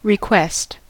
request: Wikimedia Commons US English Pronunciations
En-us-request.WAV